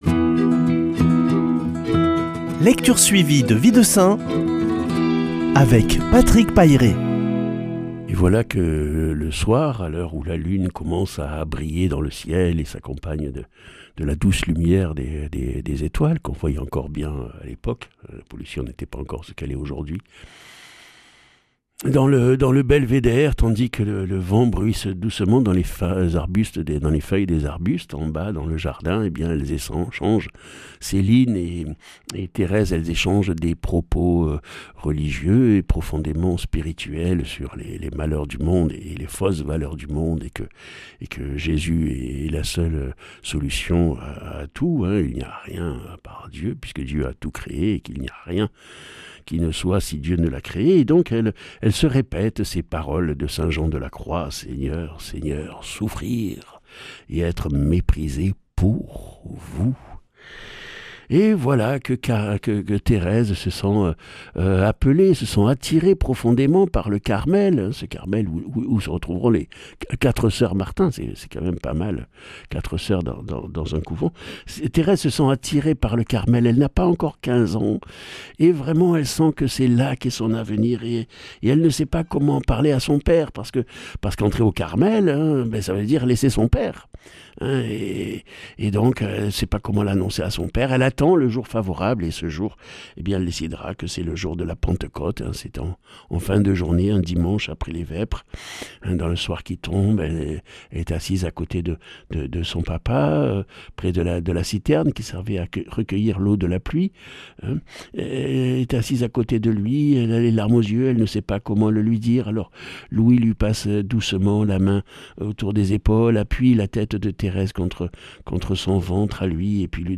Lecture suivie de la vie des saints
Chroniqueur